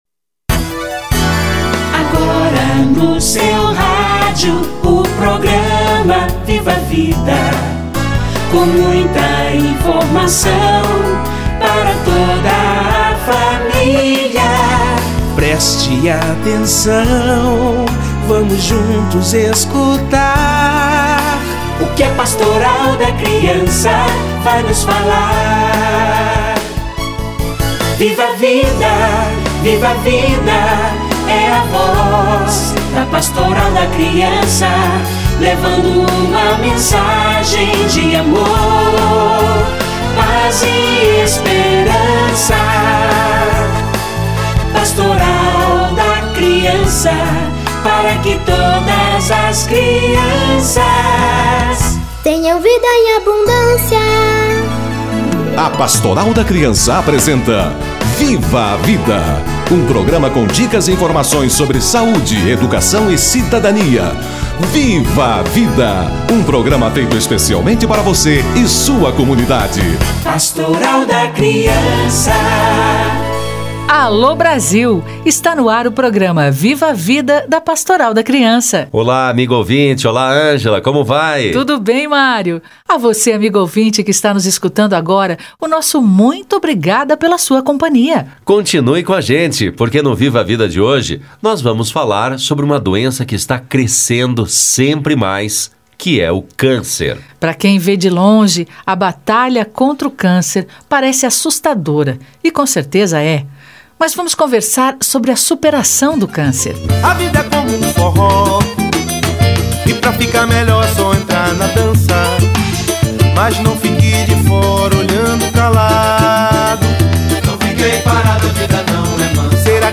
Superação do Câncer - Entrevista